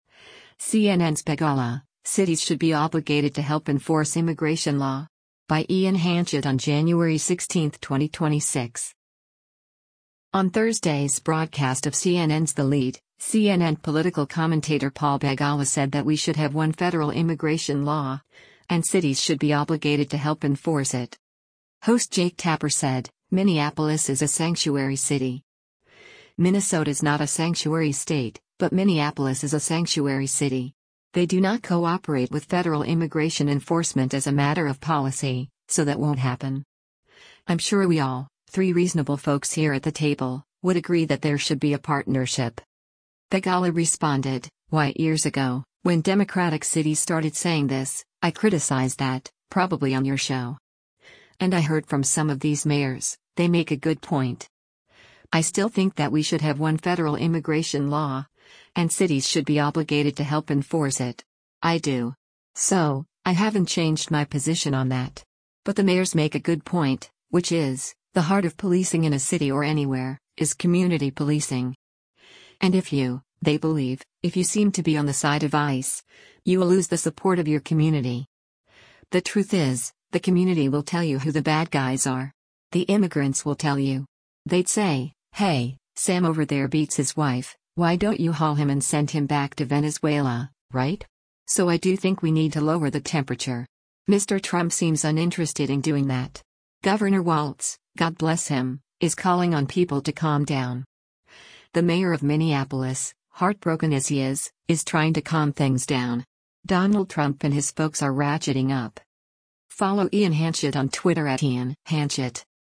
On Thursday’s broadcast of CNN’s “The Lead,” CNN Political Commentator Paul Begala said that “we should have one federal immigration law, and cities should be obligated to help enforce it.”